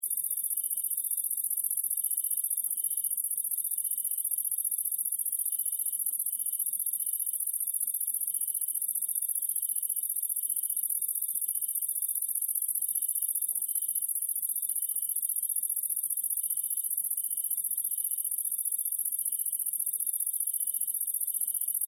Стрекотание этих насекомых идеально подходит для релаксации, звукового оформления или погружения в атмосферу дикой природы.
Звук саранчі (цвірінькання)